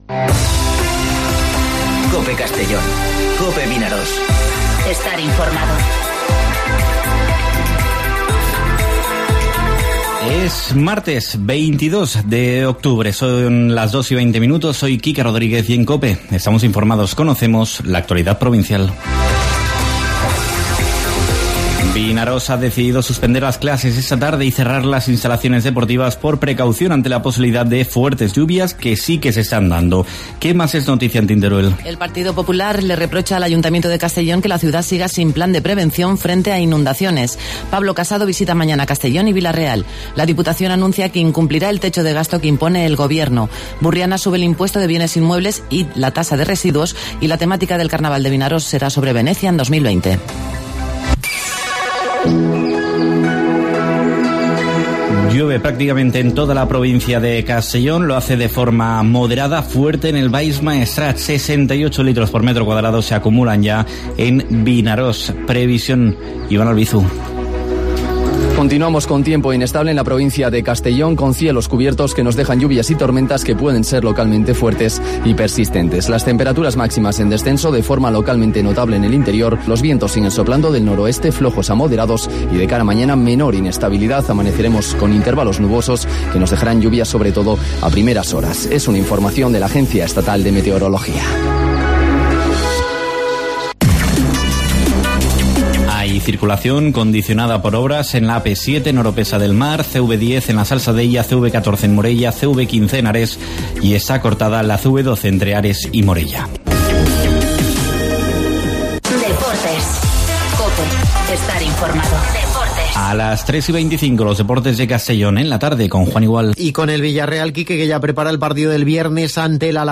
Informativo Mediodía COPE en Castellón (22/10/2019)